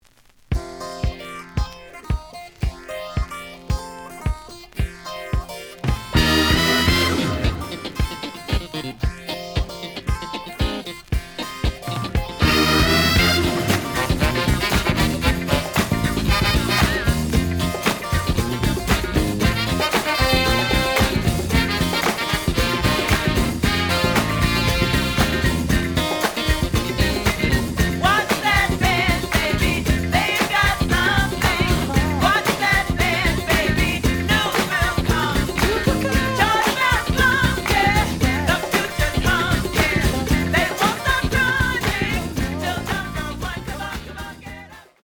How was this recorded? The audio sample is recorded from the actual item. Slight edge warp.